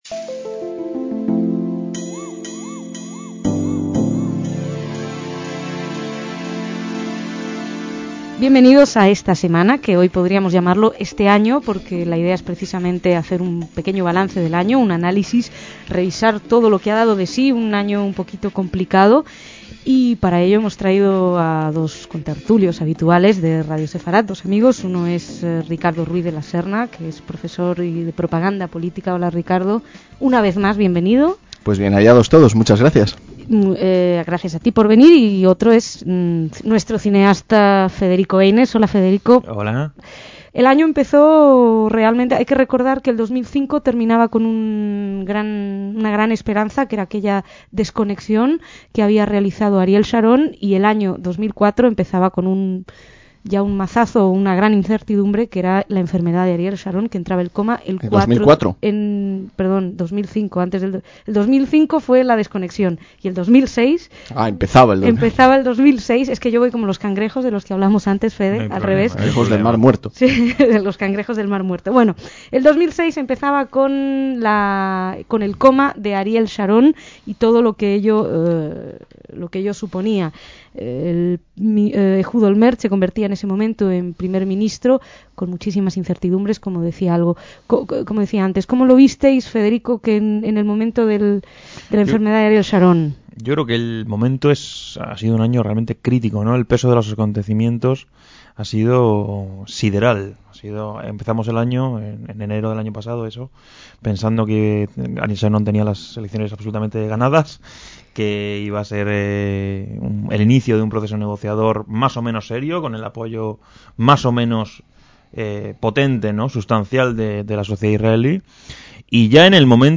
DECÍAMOS AYER (30/12/2006) - Se acababa el 2006 cuando dos contertulios nos ofrecieron un balance con lo más relevante del año.